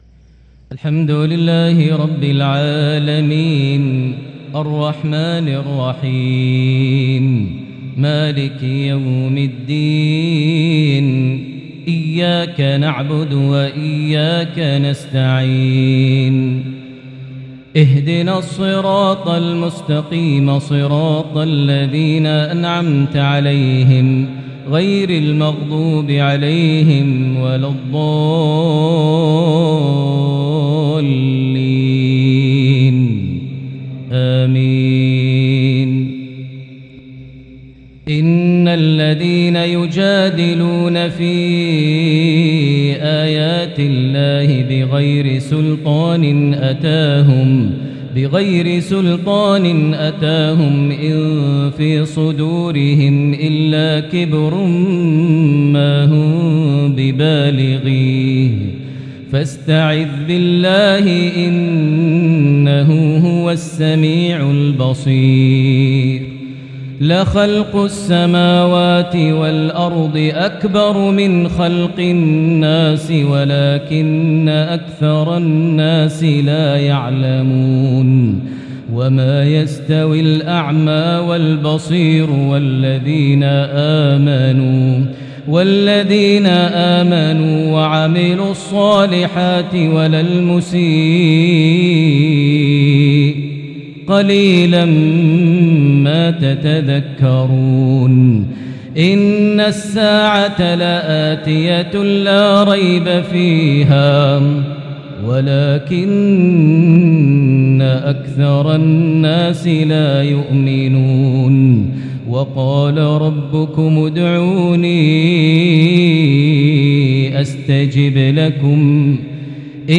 maghrib 1-3-2022 prayer from Surah Ghafir 56-65 > 1443 H > Prayers - Maher Almuaiqly Recitations